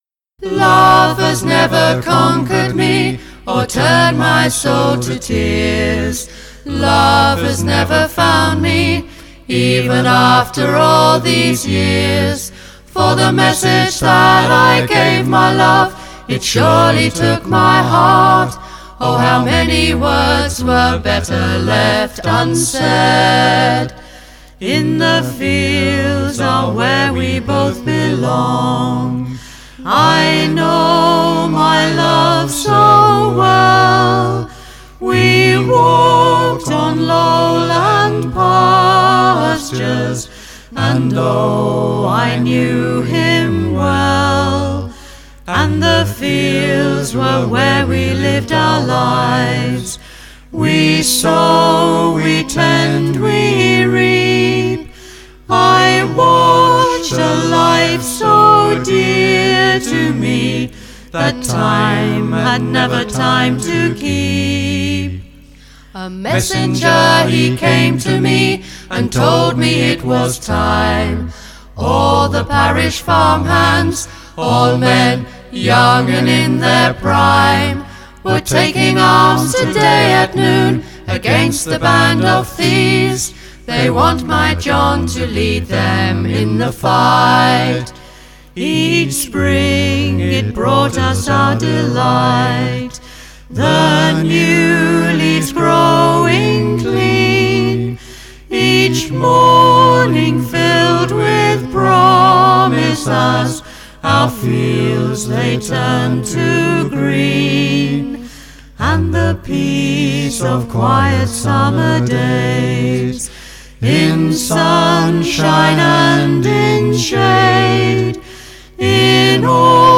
Recorded at East Surrey College